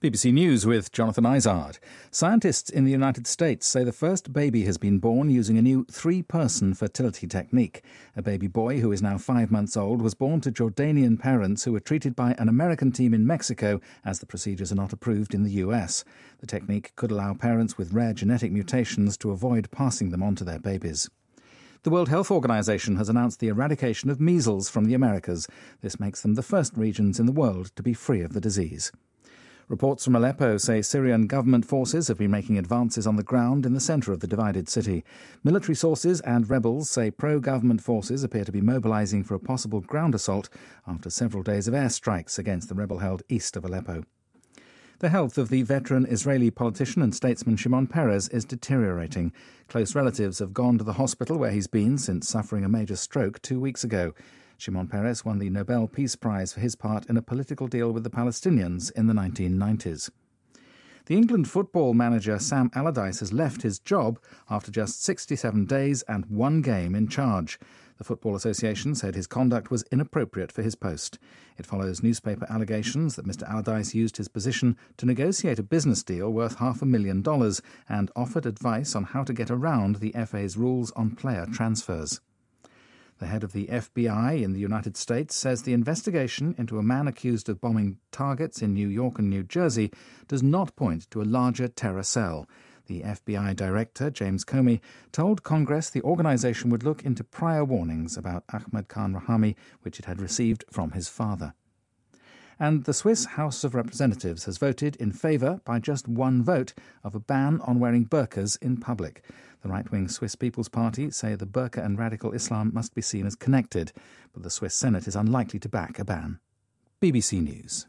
BBC news,世界卫生组织宣布美洲地区成功“消除麻疹”